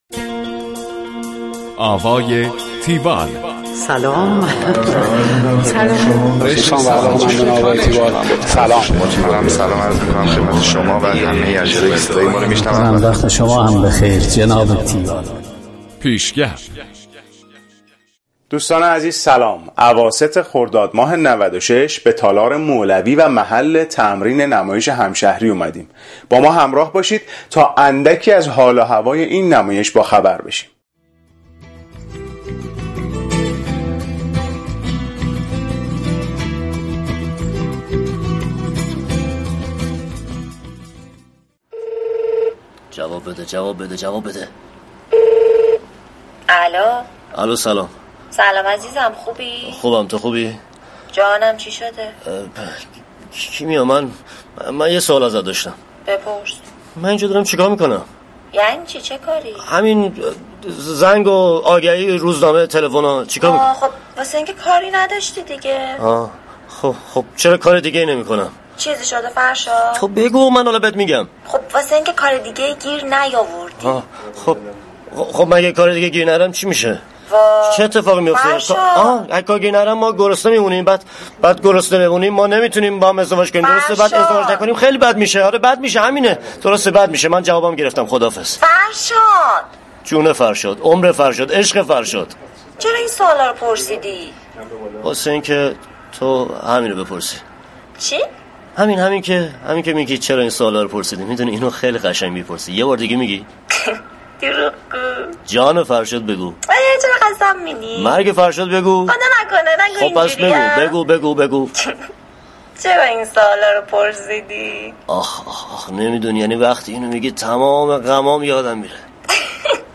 گزارش آوای تیوال از نمایش همشهری